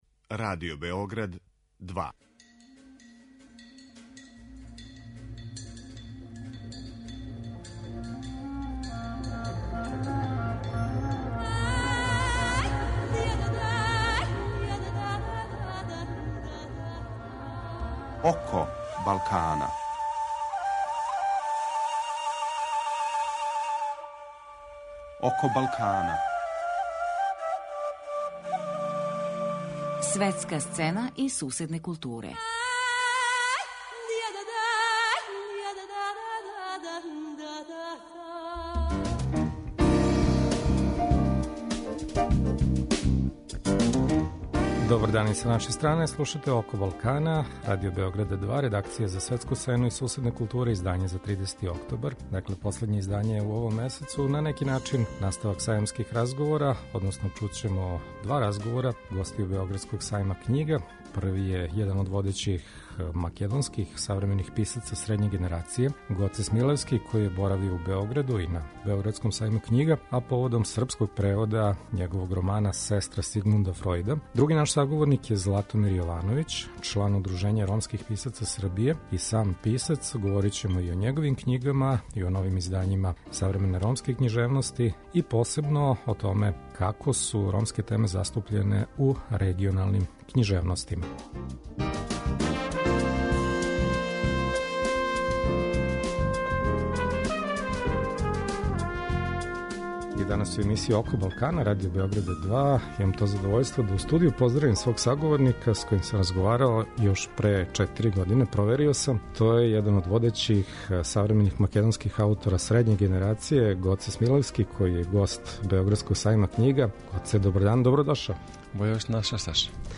И у данашњем издању настављамо сајамске разговоре.